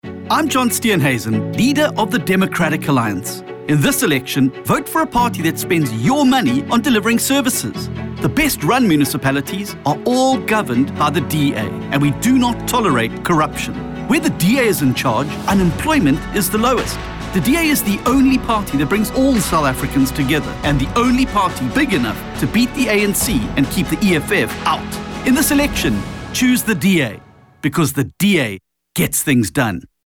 Afrikaans radio advert clips.